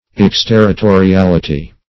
Search Result for " exterritoriality" : The Collaborative International Dictionary of English v.0.48: Exterritoriality \Ex*ter`ri*to`ri*al"i*ty\, n. 1.
exterritoriality.mp3